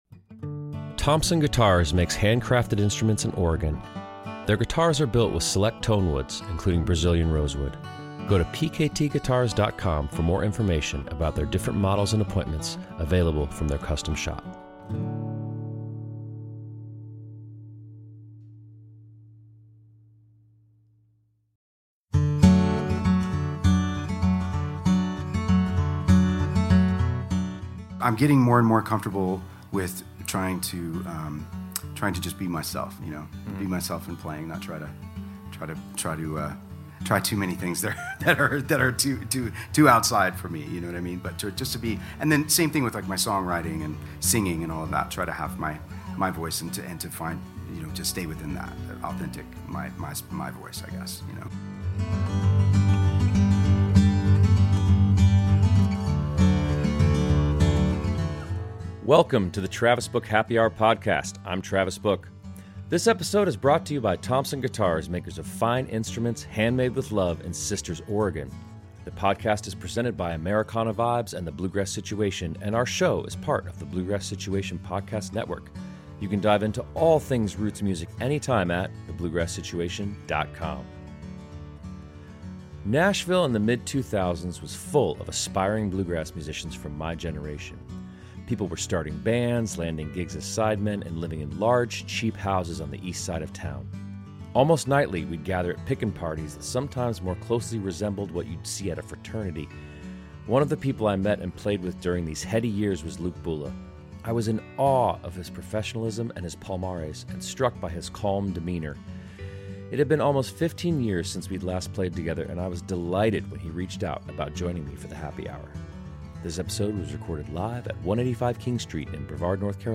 LISTEN: APPLE • SPOTIFY • STITCHER • AMAZON • MP3 This episode was recorded live at 185 King Street in Brevard, North Carolina on May 5, 2024.